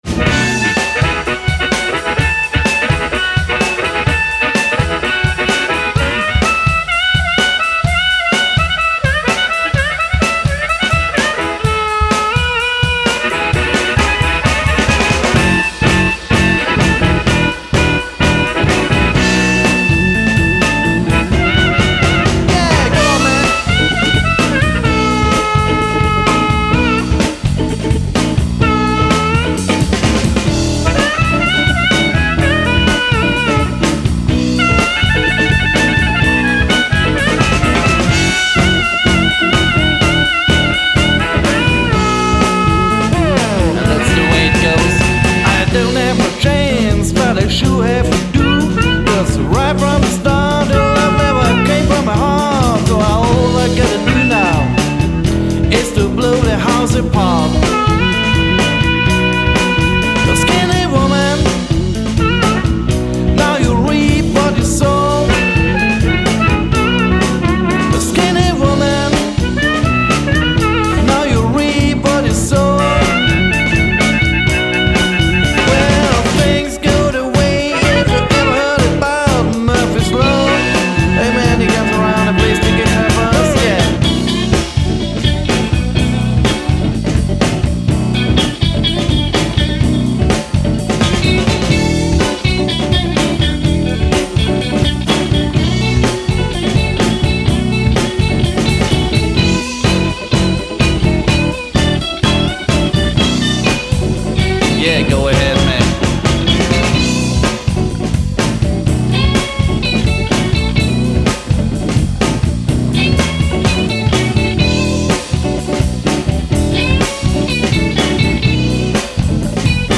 Blues&Rock´n´Roll-Band aus OÖ